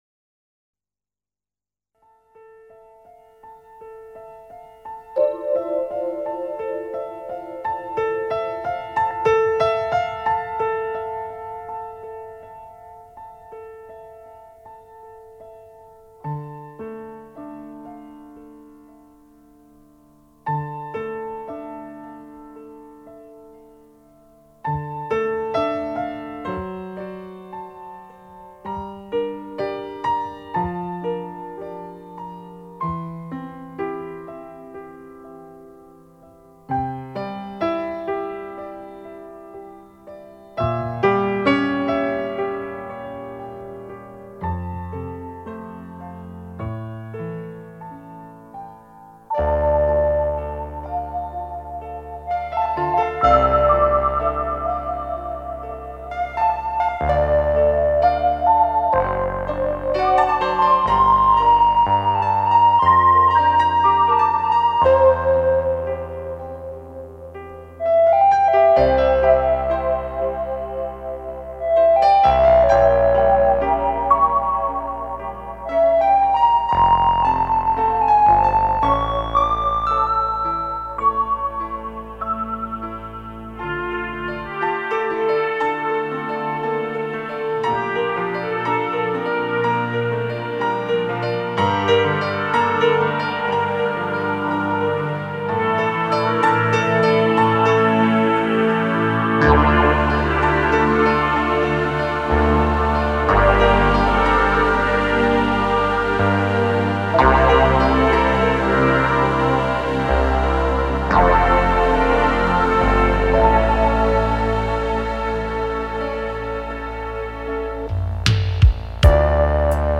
Жанр: Electronic; Битрэйт